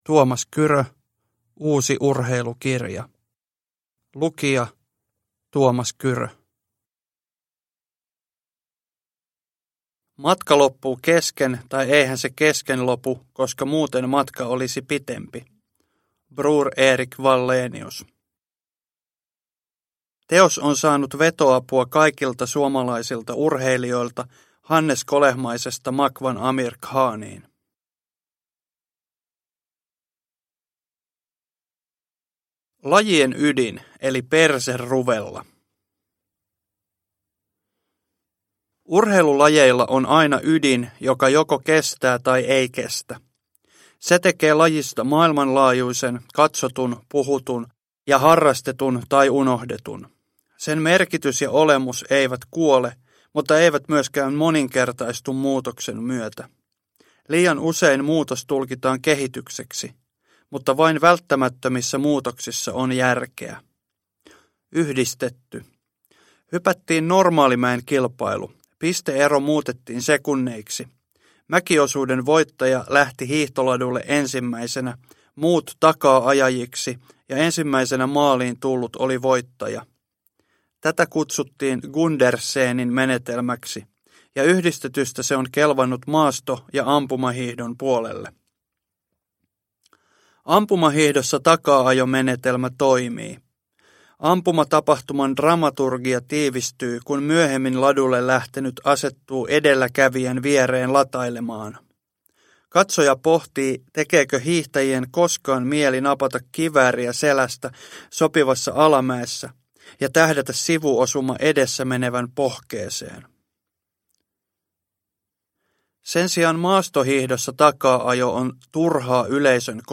Uusi Urheilukirja (ljudbok) av Tuomas Kyrö